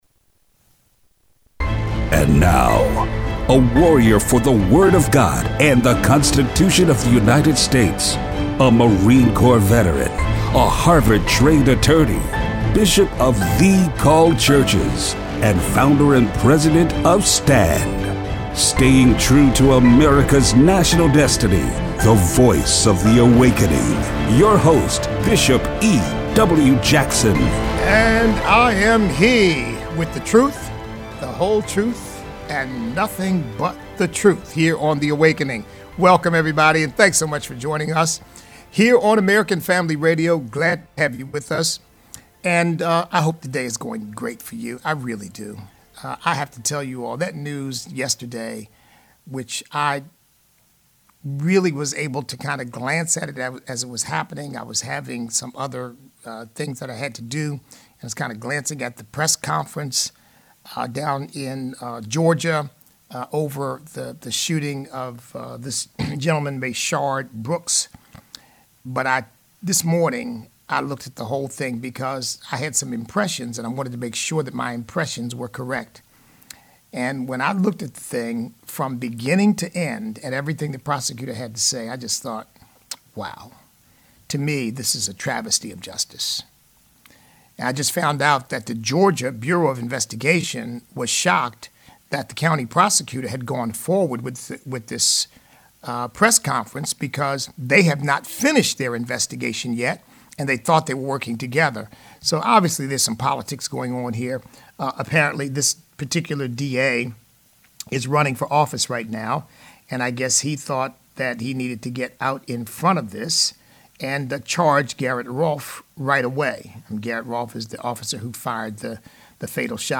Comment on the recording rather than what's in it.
Mob justice at it's worst. Listener call-in.